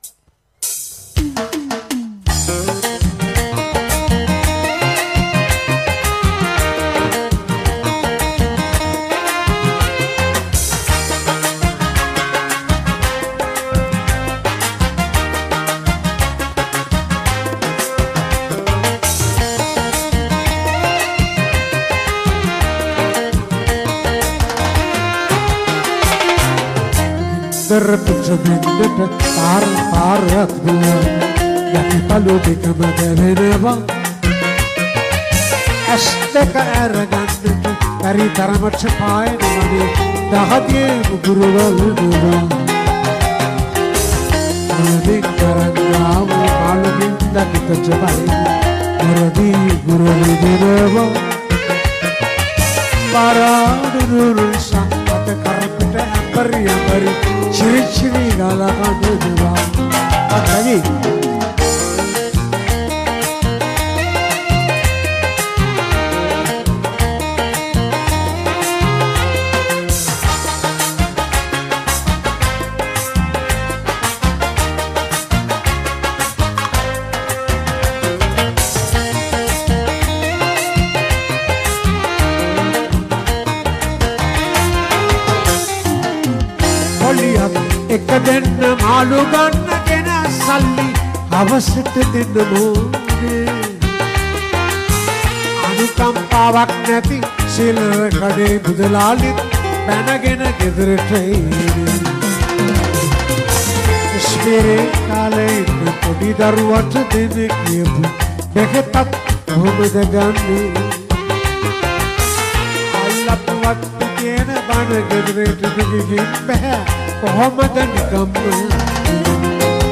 Live in Harindragama